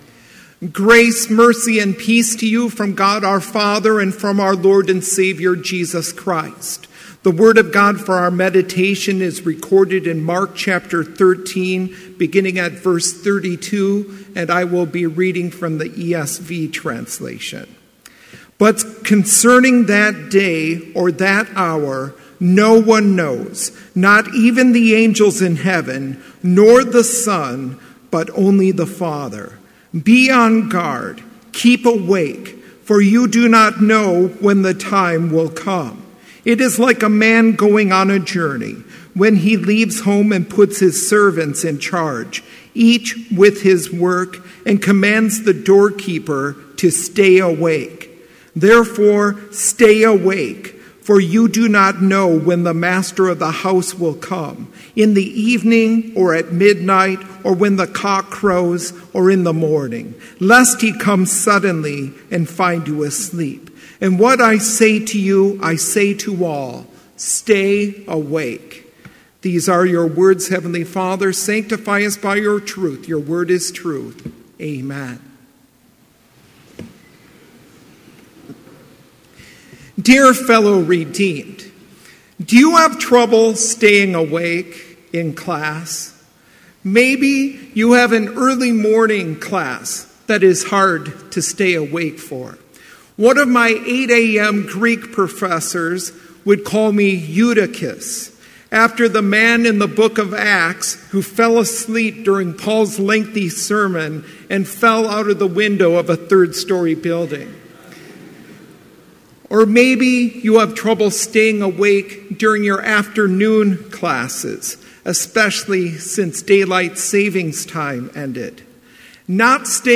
Complete service audio for Chapel - November 14, 2017